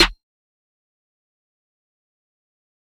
Snares
Sn (Backyard).wav